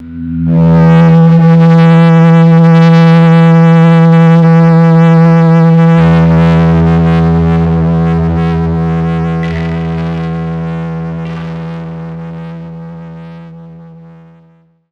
GUITARFX 9-R.wav